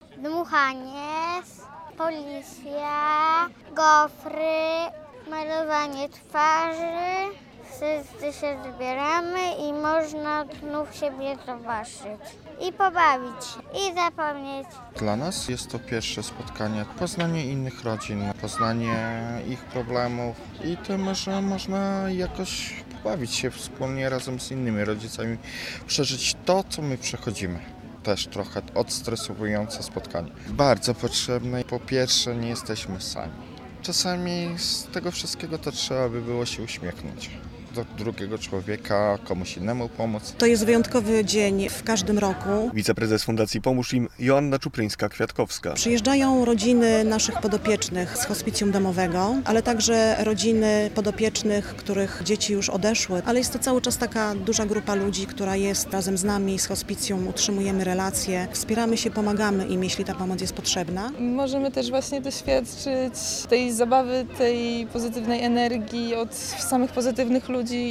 Dzień Rodziny w Fundacji „Pomóż Im” - relacja